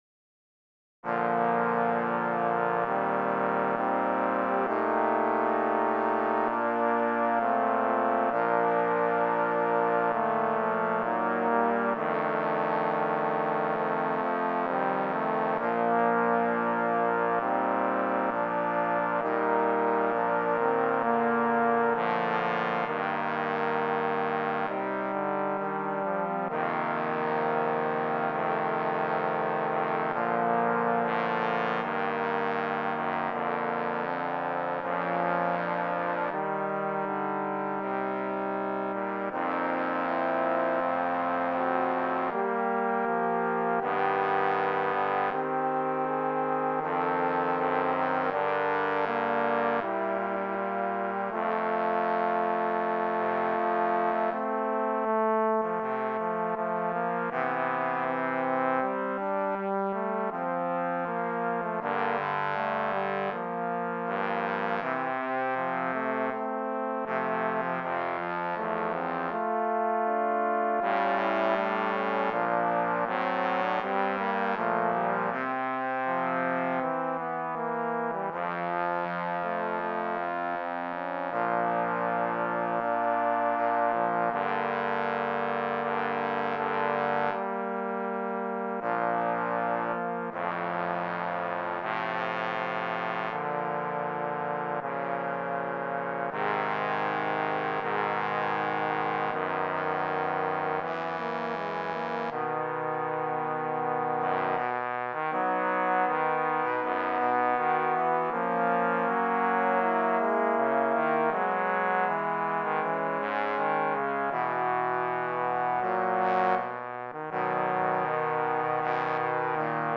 Voicing: Trombone Quartet